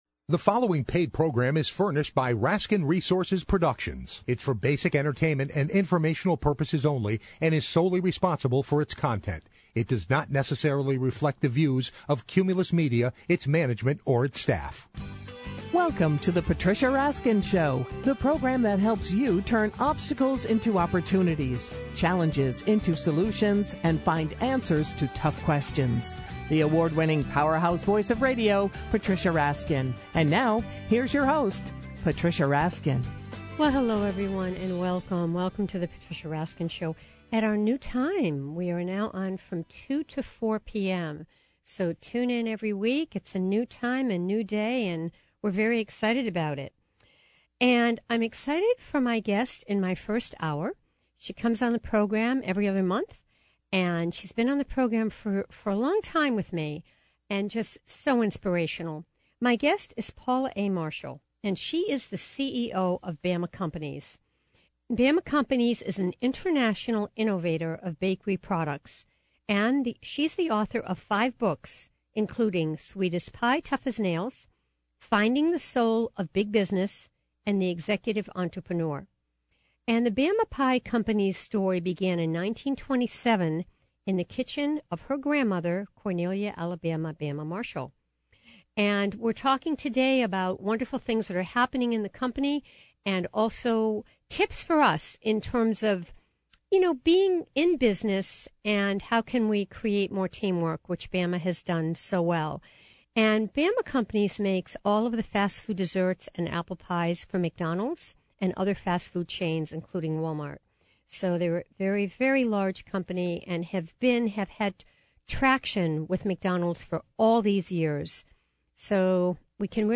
Radio Interview
Radio interview